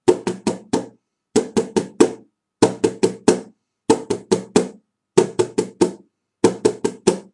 洗涤
描述：有人在厨房的水槽里洗碗。包括水声。用Zoom H4n修改。
标签： 厨房 水槽 洗涤剂 餐具 抽头 漏极
声道立体声